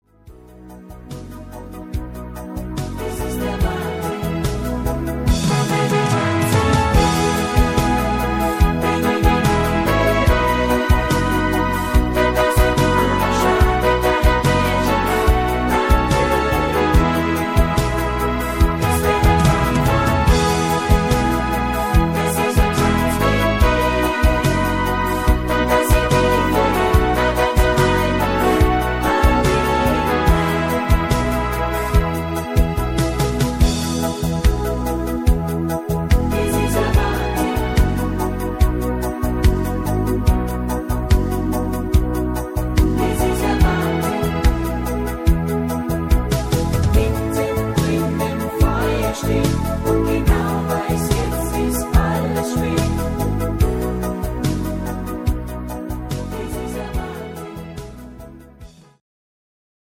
Rhythmus  Beat Cha cha
Art  Deutsch, Volkstümlicher Schlager